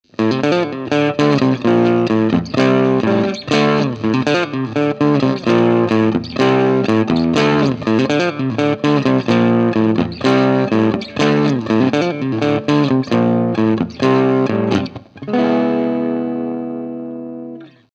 一応、普通のストラトキャスターから直結した
音をアップしておきます。もちろんフルヴォリュームです。